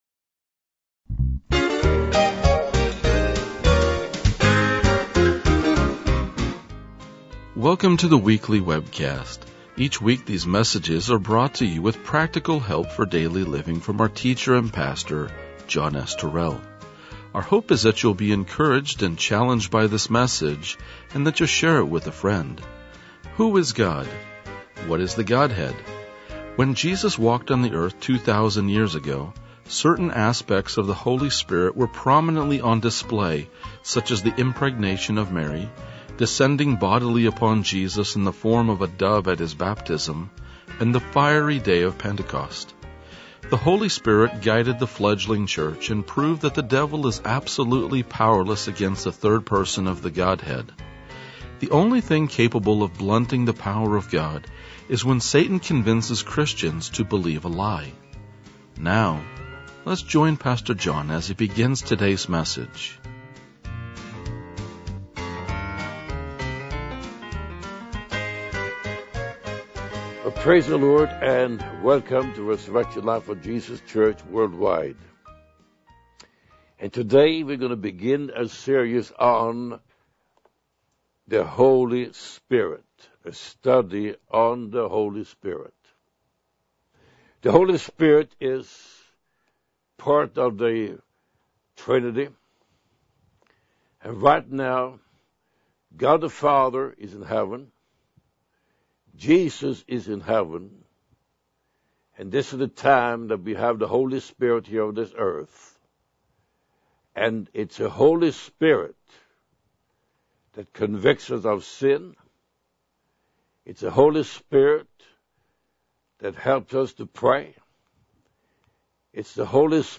RLJ-2016-Sermon.mp3